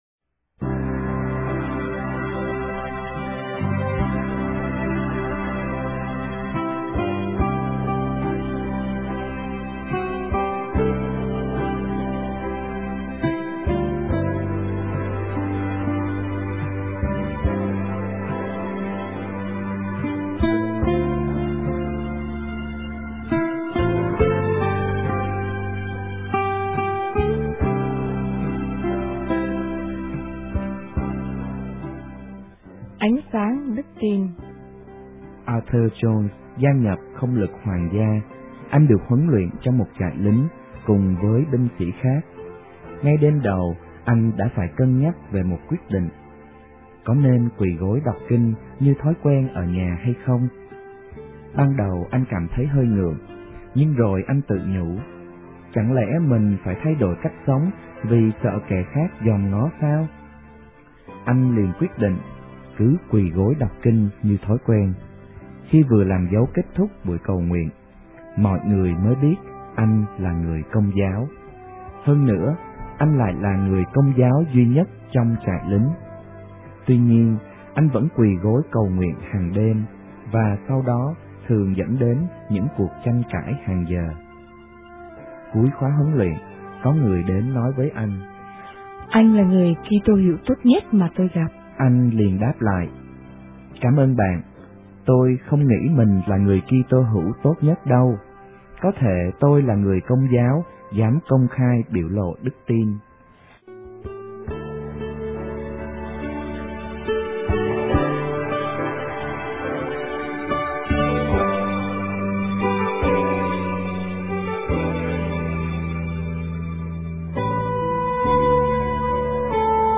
* Thể loại: Suy niệm